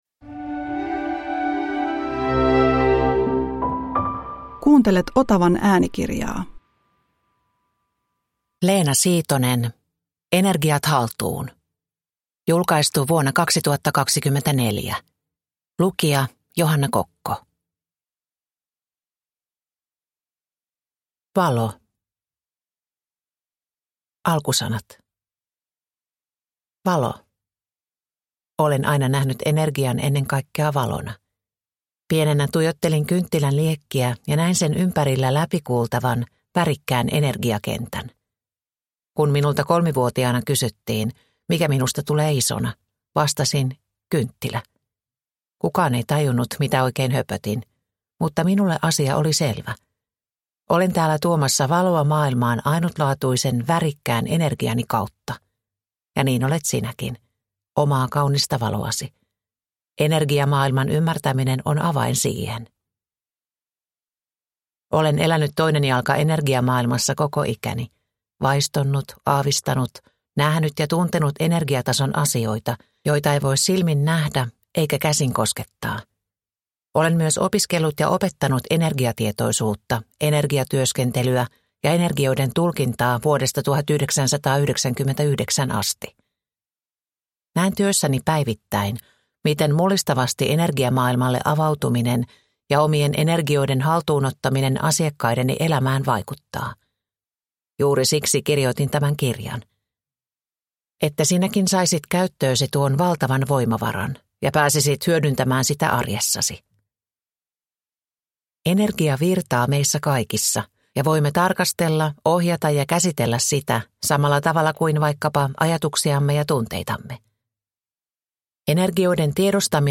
Energiat haltuun – Ljudbok